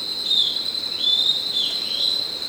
Variant call recorded Estancia Kanguery, PN San Rafael